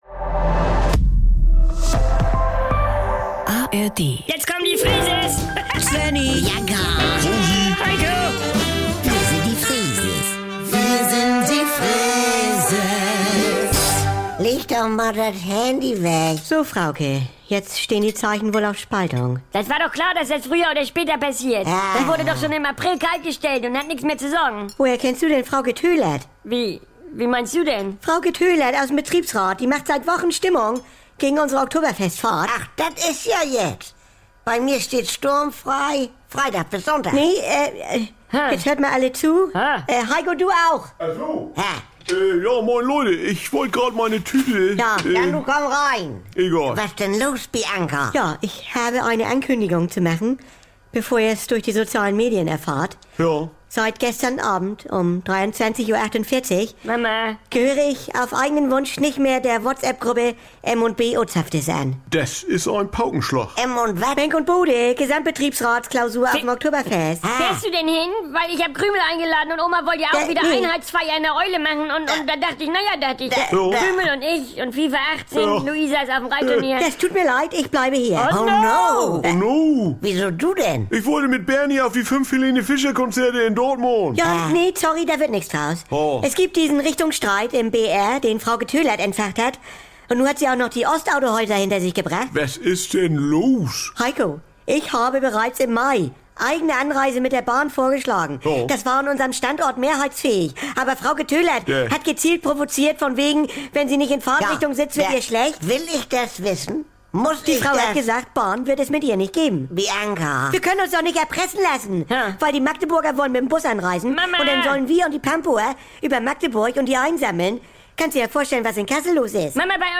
Kult-Comedy direkt aus dem Mehrgenerationen-Haushalt der Familie